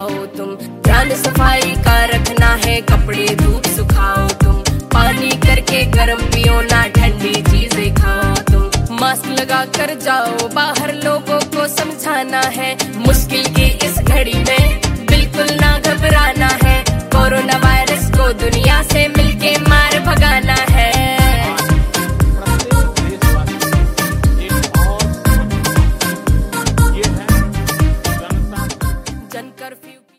India ,Pop